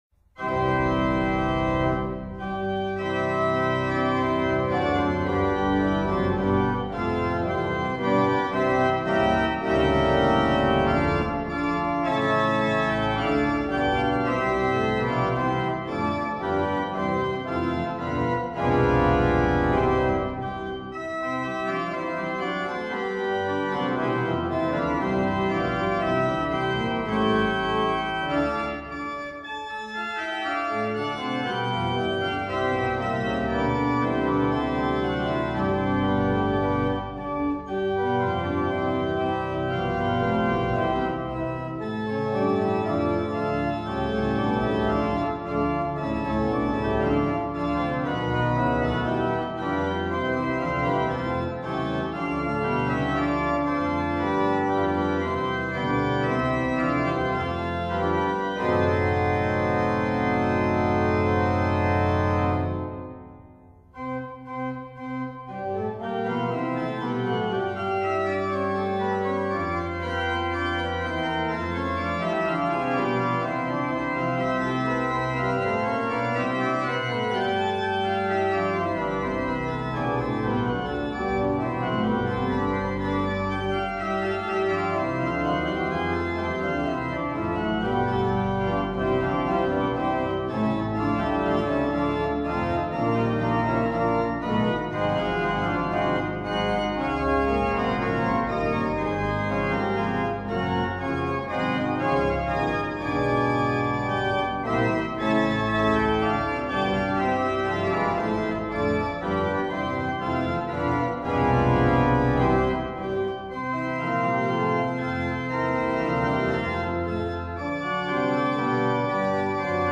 Hörbeispiele der Orgel in Satow:
3. Satz: Finale, Allegro moderato aus:
Fantasie über „Ein feste Burg ist unser Gott“ Opus 33b, für Orgel vierhändig